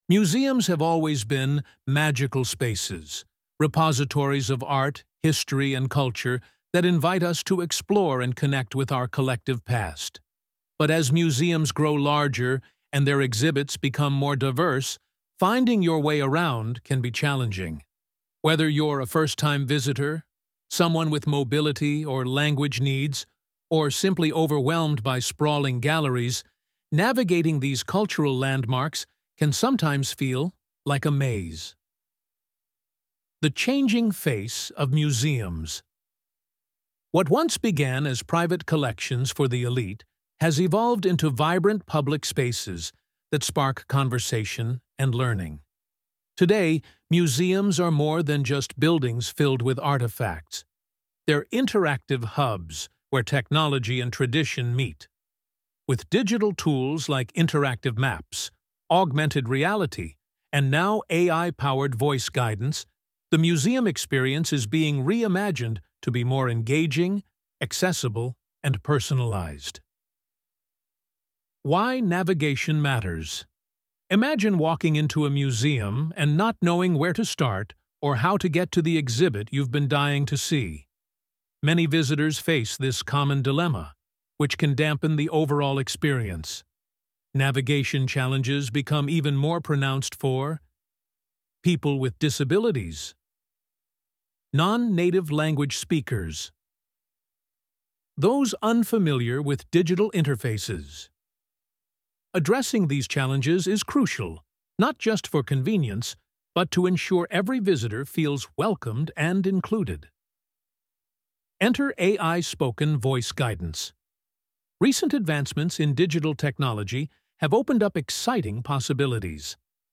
Blog audio as read by one of our AI narrators.